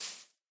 step_grass1.mp3